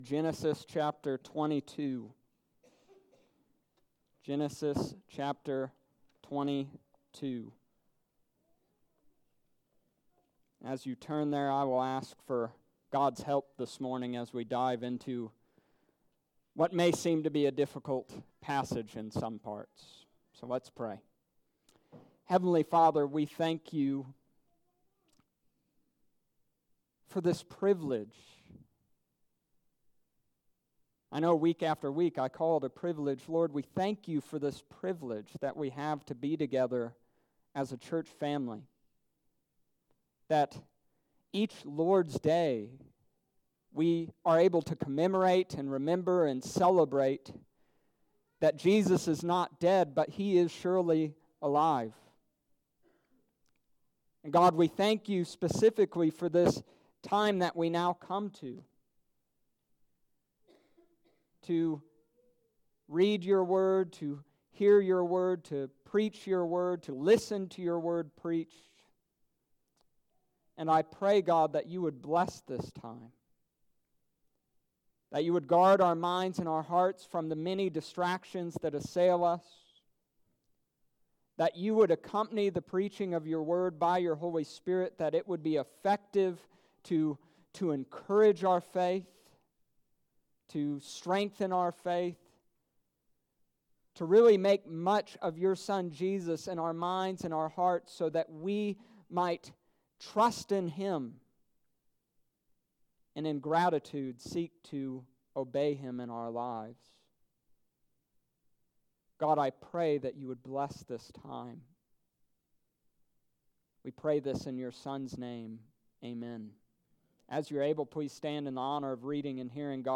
The Nation Blessing Seed: Genesis Chapter 22 verses 1-19 Dec. 15th, 2019 Sunday Morning Service.